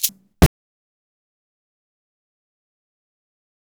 133SHAK04.wav